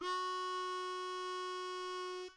口琴快速翻转弯02
描述：我录了一个口琴裂缝的样本。
标签： 口琴 钥匙 G 单身 裂谷
声道立体声